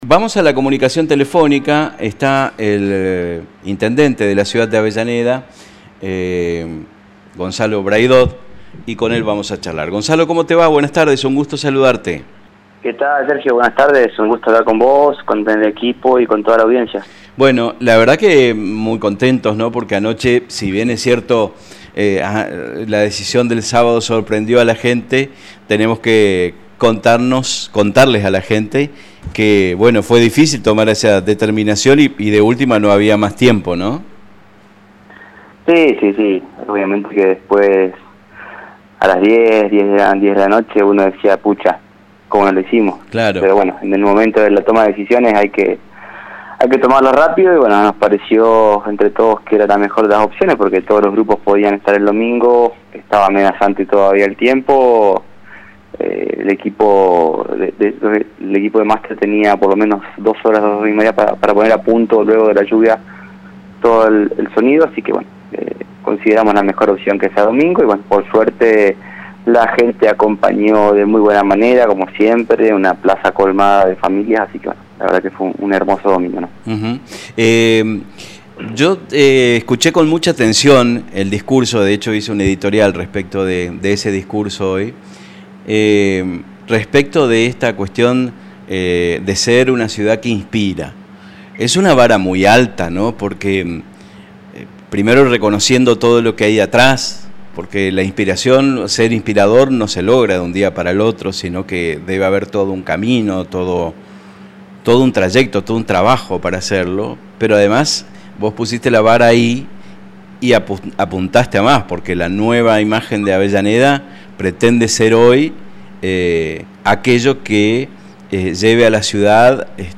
En una reciente entrevista en Vivos y Sueltos, el intendente Gonzalo Braidot compartió su visión sobre el futuro de la ciudad, destacando la importancia de una planificación estratégica en el camin…